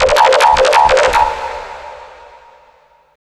OLDRAVE 1 -L.wav